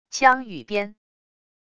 枪与鞭wav音频